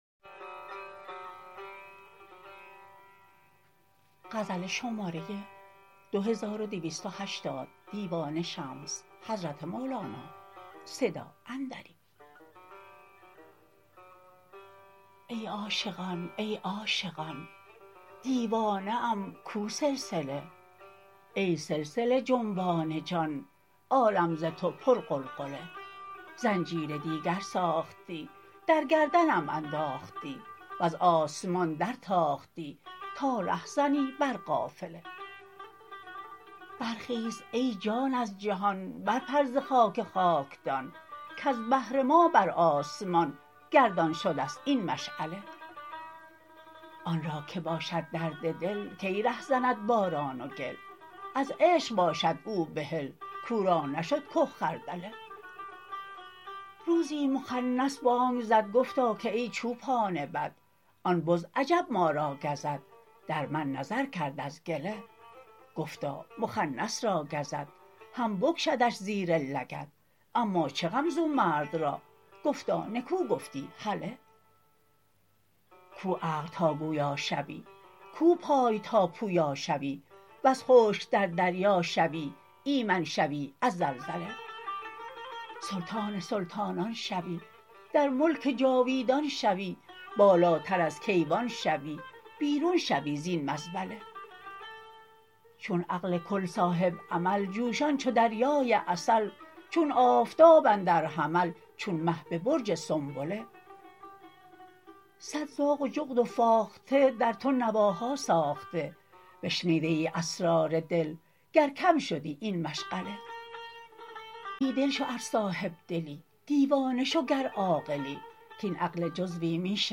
خوانش شعر